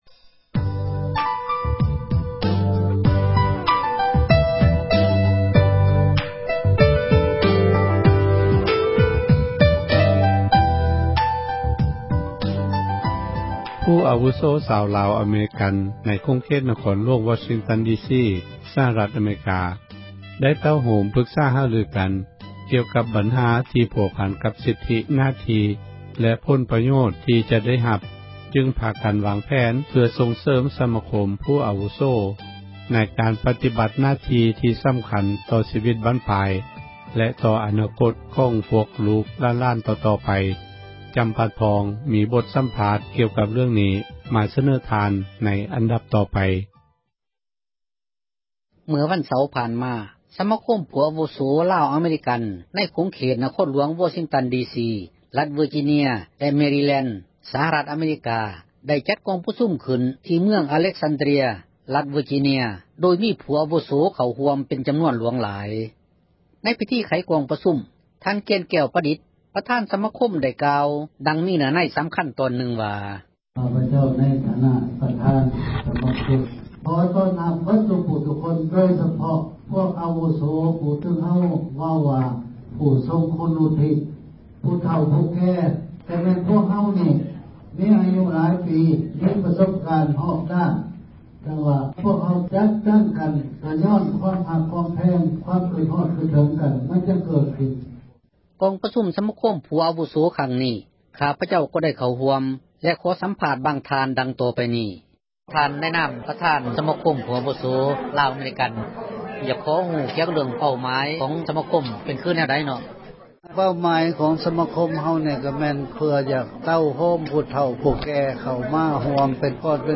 ສັມພາດຜູ້ອາວຸໂສ ໃນວໍຊິງຕັນດີຊີ